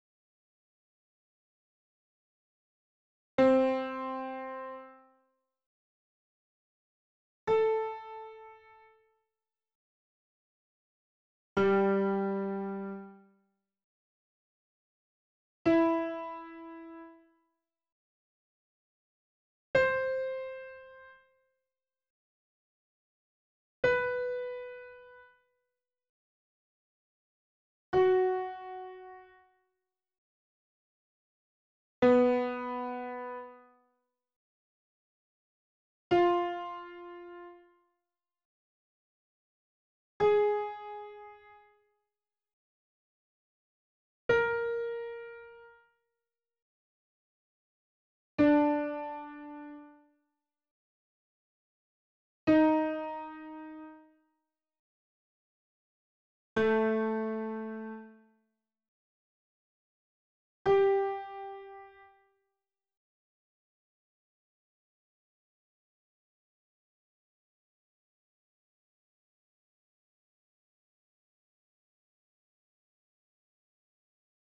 Lesson 2 - Ex 1 - Random Note Pitching